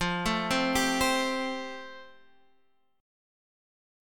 F Major Add 9th